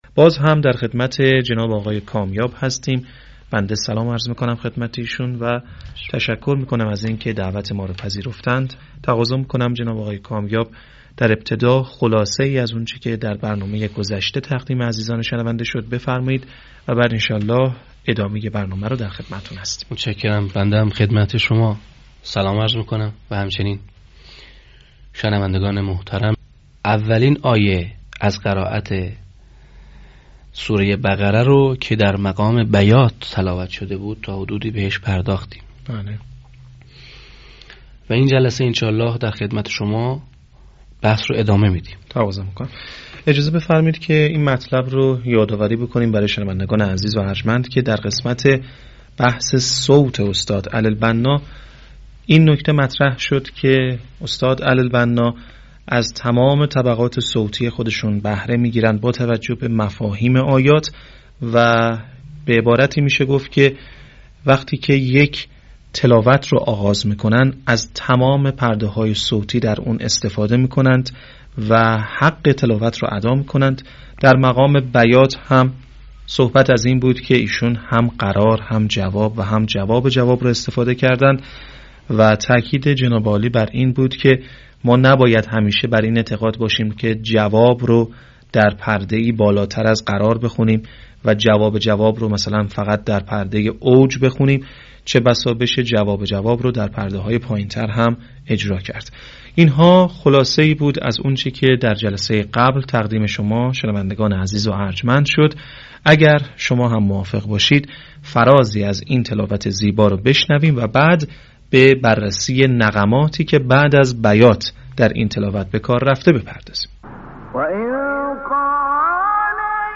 صوت | تحلیل تلاوت «علی البناء» در مقام‌های بیات و صبا
تحلیل تلاوت محمود علی البناء بخش دوم